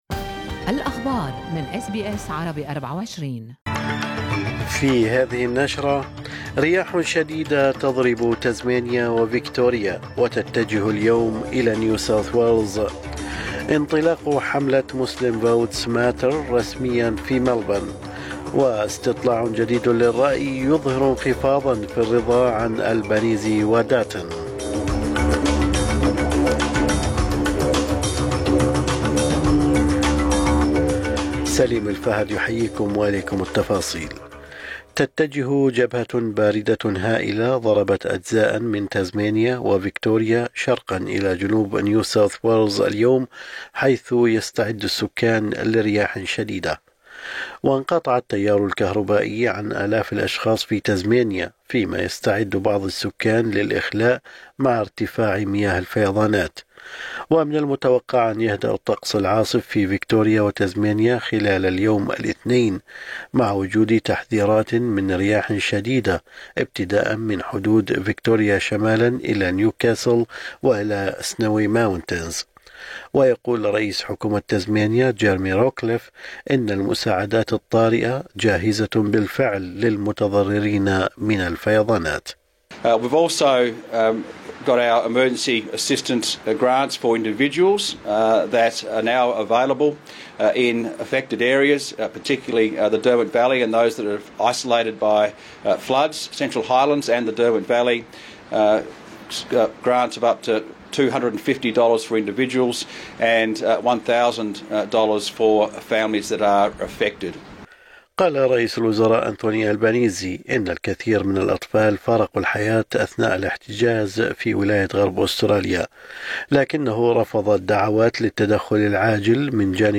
نشرة أخبار الصباح 2/9/2024